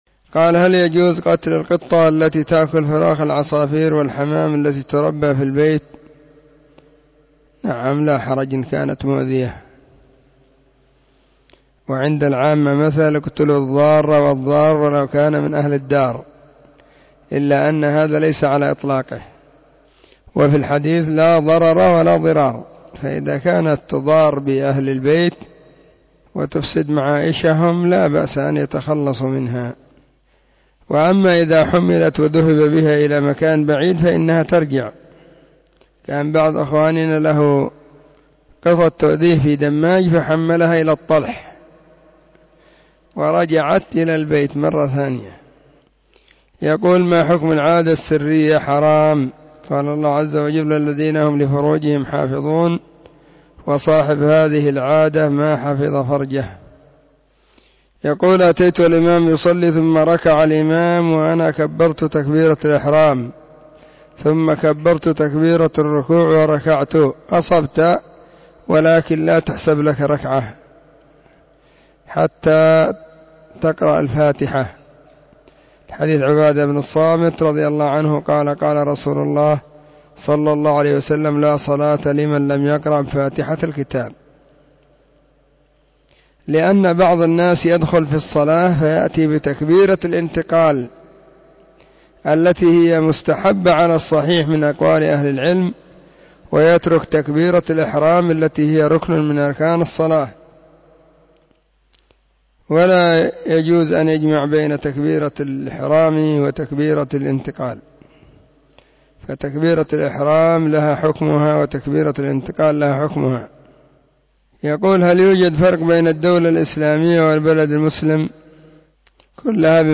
سلسلة الفتاوى الصوتية